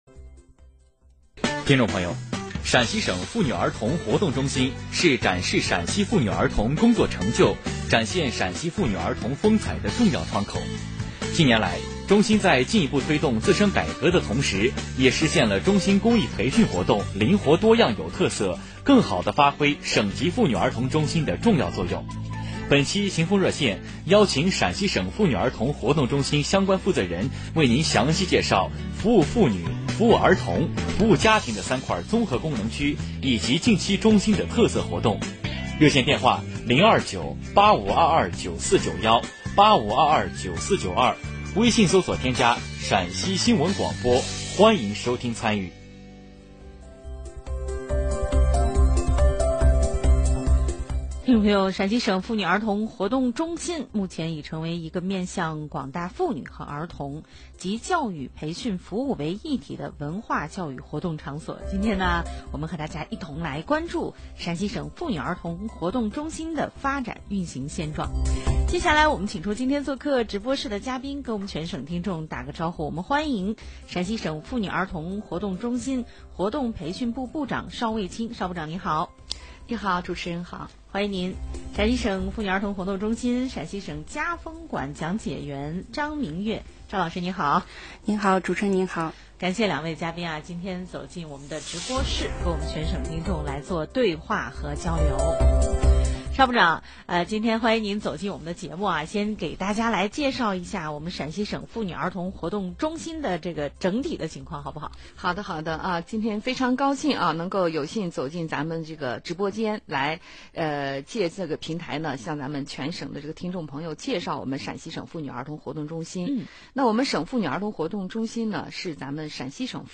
省妇女儿童活动中心服务阵地功能采访纪实（直播间全程音频）
9月29日上午，陕西省妇女儿童活动中心有关业务负责同志受邀走进陕西广播电视台新闻广播《秦风热线》直播间，就省妇儿中心“服务妇女、服务儿童、服务家庭”的三块综合功能区域、陕西省家风馆等内容以及近期中心的特色活动做专题现场采访、介绍。